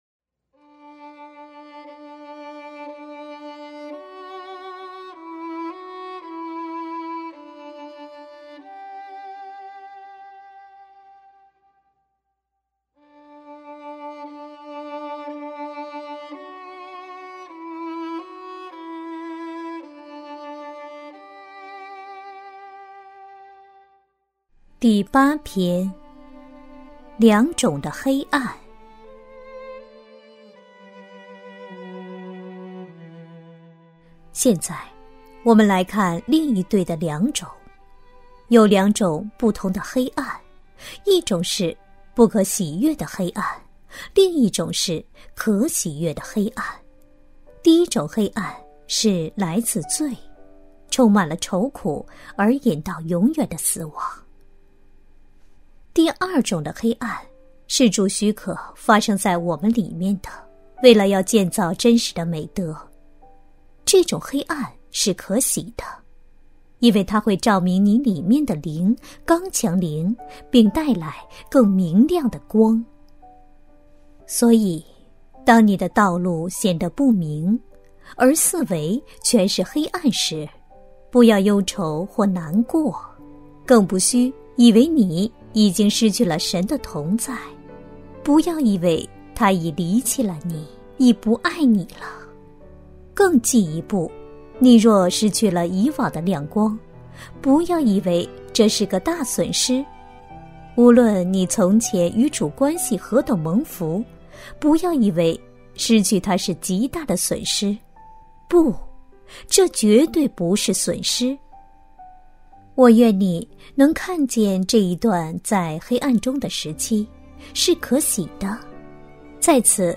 首页 > 有声书 | 灵性生活 | 灵程指引 > 灵程指引 第八篇：两种的黑暗